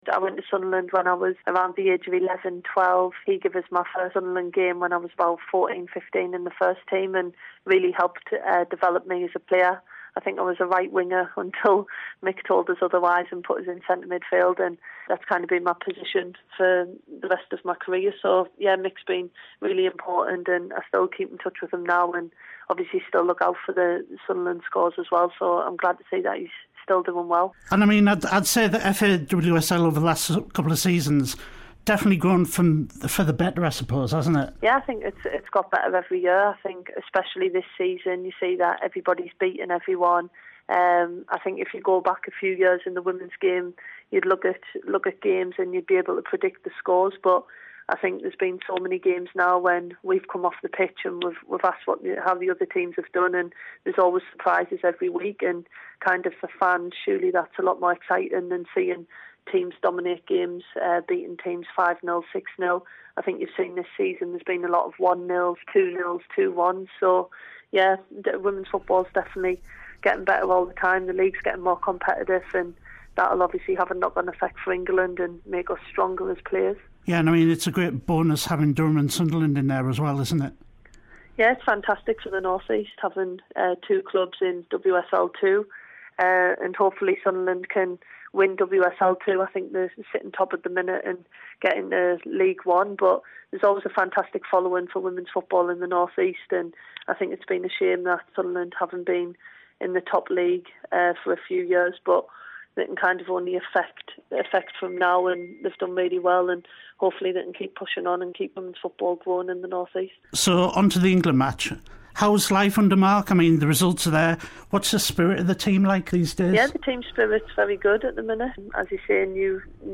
Jill Scott speaks ahead of the England v Sweden friendly this weekend at Hartlepool.